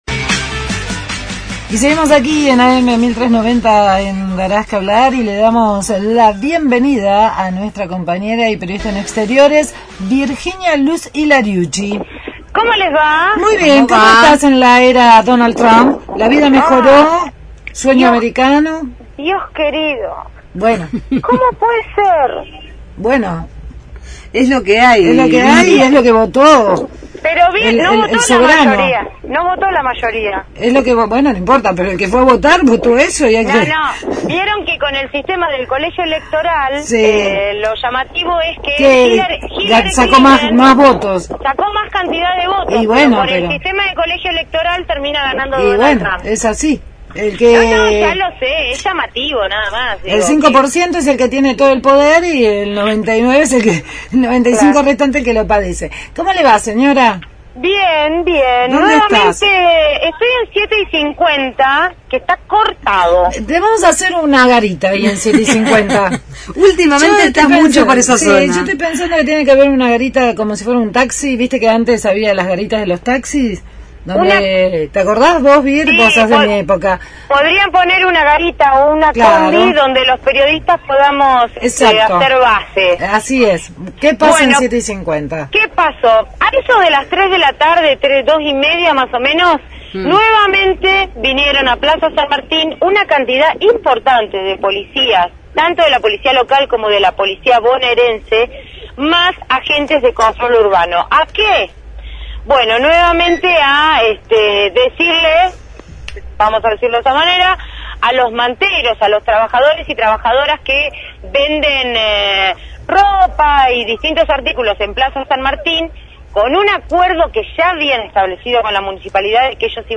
desde 7 y 50 con los vendedores ambulantes de Plaza San Martín que cortan la calle porque nuevamente los desalojaron de la plaza.